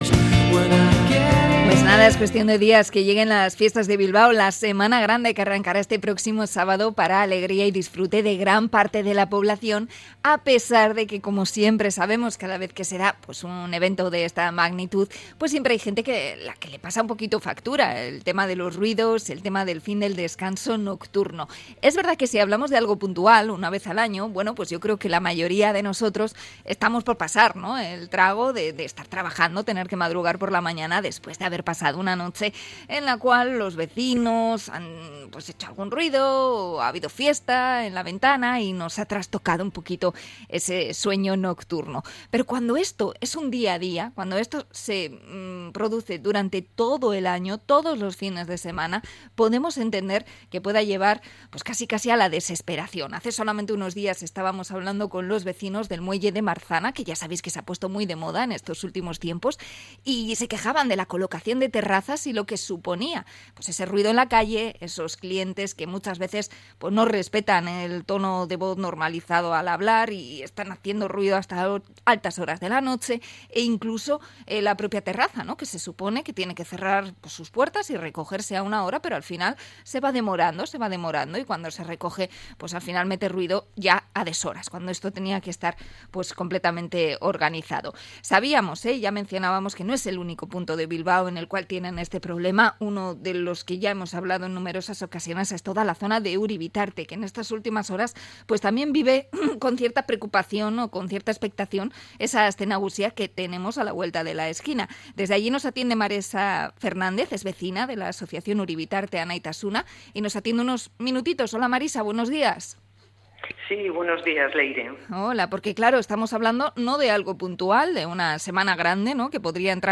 Entrevista a los vecinos de Uribitarte por el ruido de eventos, discotecas y terrazas